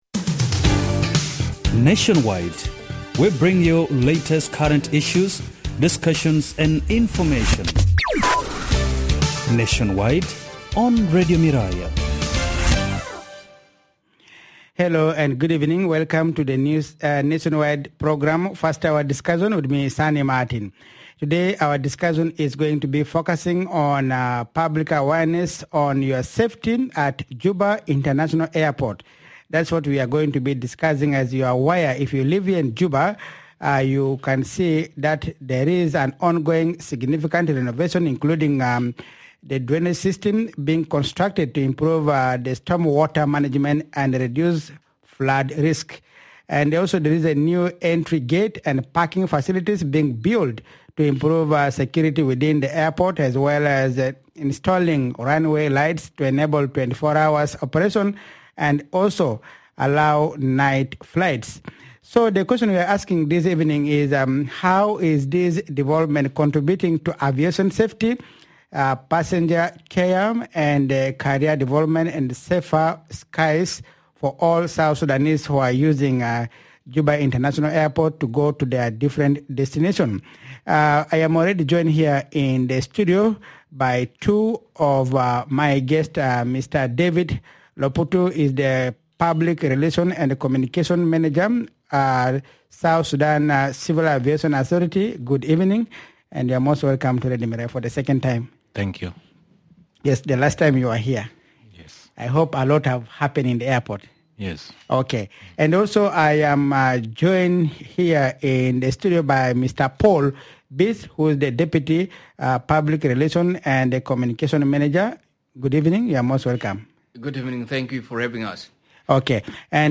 In this episode, we take a closer look at airport safety, passenger care and the growing opportunities within South Sudan’s aviation sector. Officials from the South Sudan Civil Aviation Authority explain why protecting airport spaces, improving passenger experience and investing in aviation careers are key to safer skies and national development.